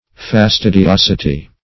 Search Result for " fastidiosity" : The Collaborative International Dictionary of English v.0.48: Fastidiosity \Fas*tid`i*os"i*ty\, n. Fastidiousness; squeamishness.
fastidiosity.mp3